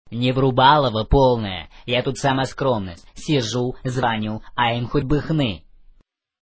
Рингтоны пародии